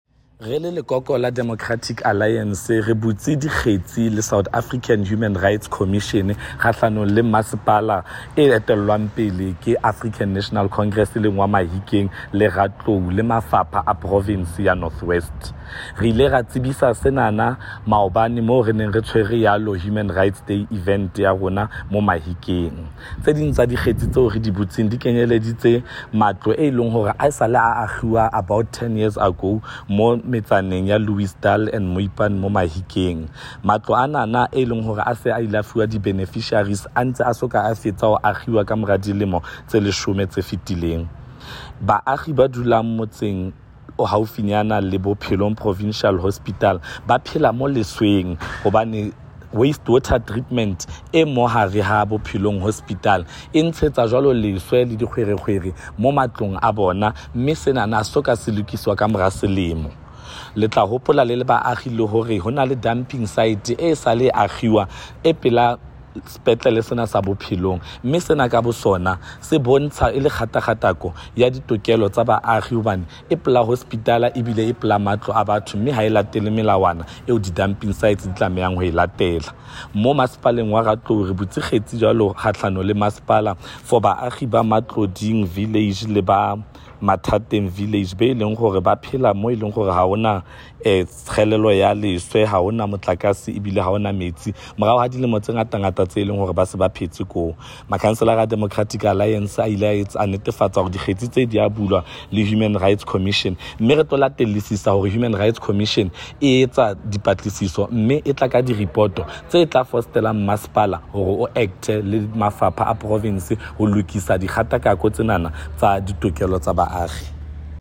Note to Broadcasters: Find linked soundbites in